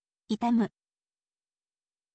itamu